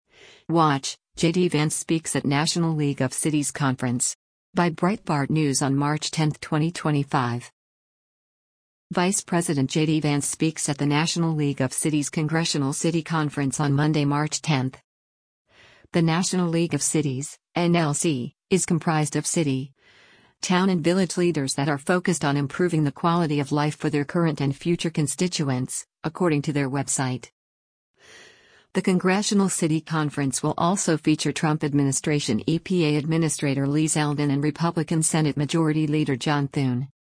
Vice President JD Vance speaks at the National League of Cities’ Congressional City Conference on Monday, March 10.